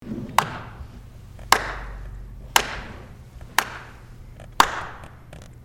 Clap Sound